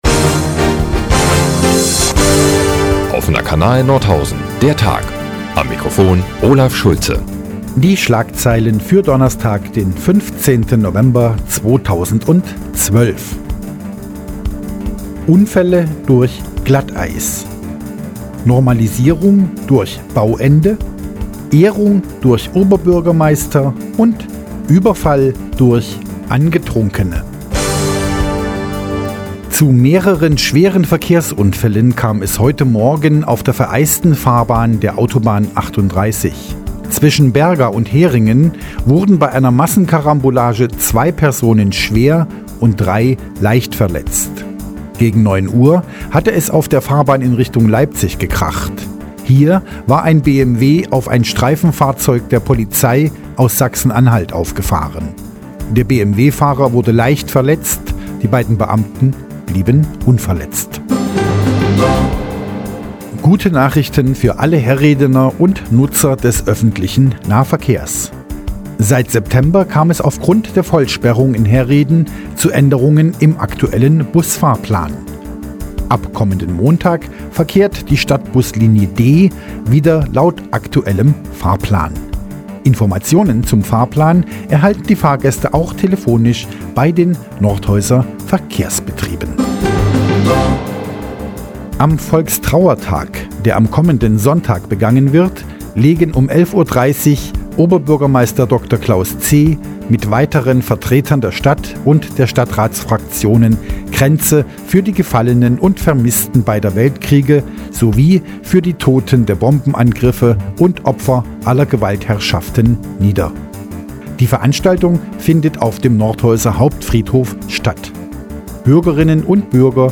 Die tägliche Nachrichtensendung des OKN ist jetzt hier zu hören. Heute mit Unfall, Überfall, Kranzniederlegung und Baustopp.